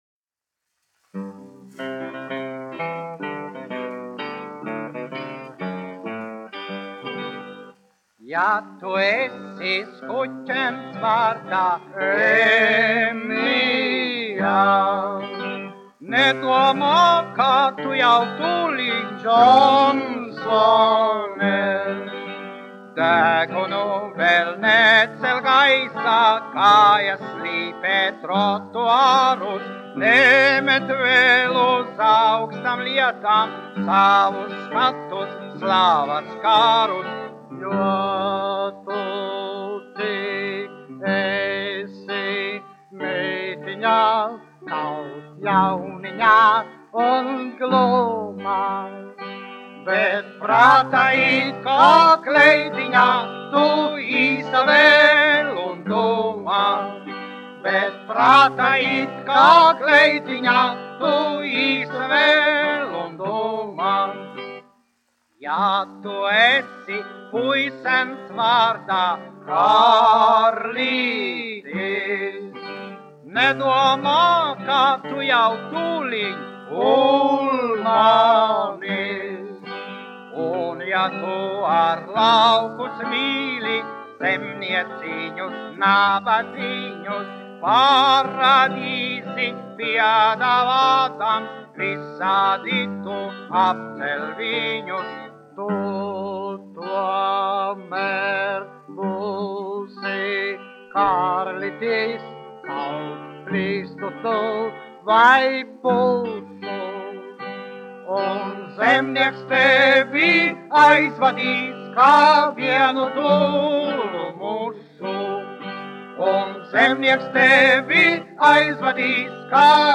1 skpl. : analogs, 78 apgr/min, mono ; 25 cm
Humoristiskās dziesmas
Skaņuplate
Latvijas vēsturiskie šellaka skaņuplašu ieraksti (Kolekcija)